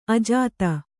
♪ ajāta